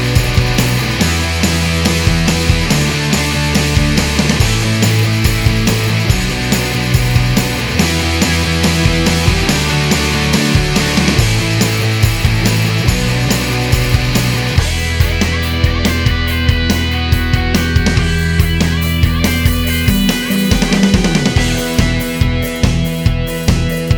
Minus Main Guitar Rock 3:42 Buy £1.50